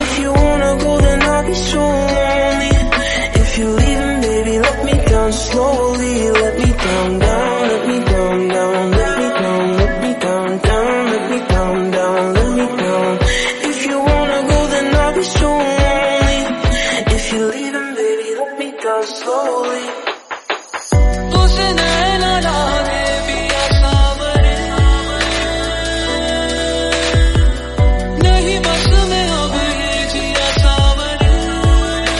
remix song ringtone